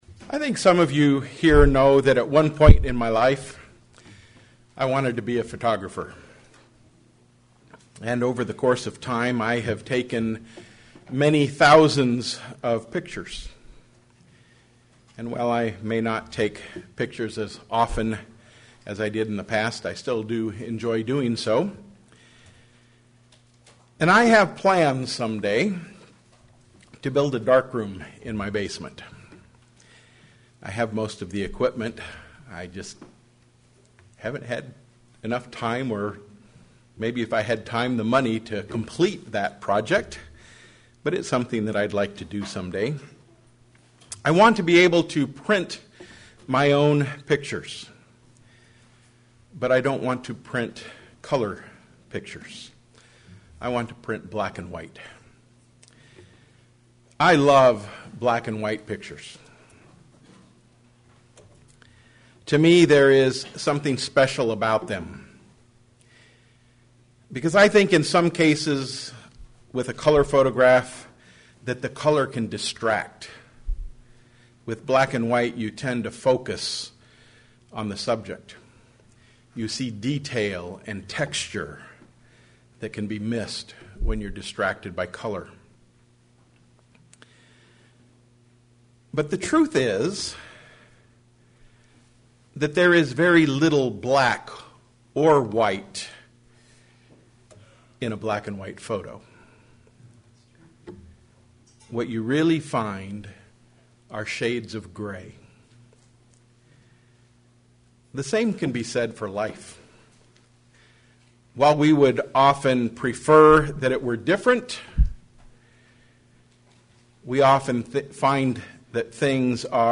In reality, it is shades of gray sermon Studying the bible?